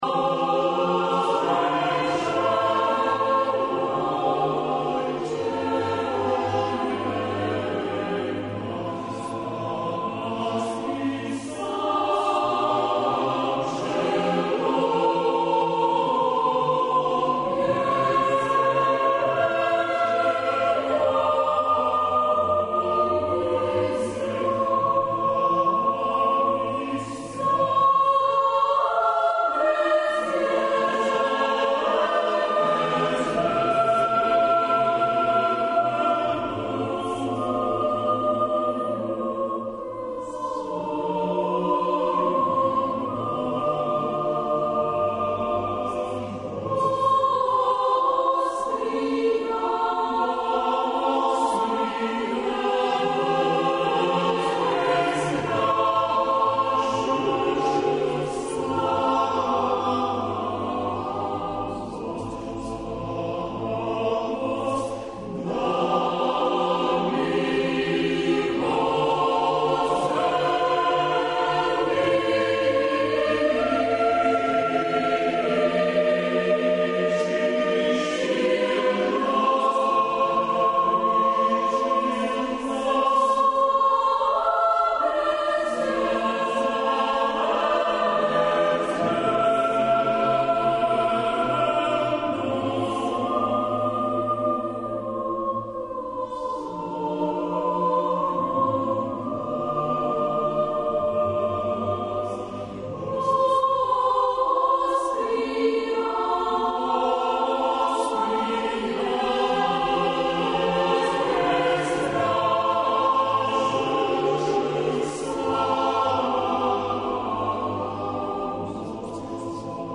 Емисија посвећена православној духовној музици
у централном делу емисије слушаћете српске народне божићне песме које изводе чланови Хора "Свети Стефан Дечански"
који су остварени у новосадској Саборној цркви 2004.године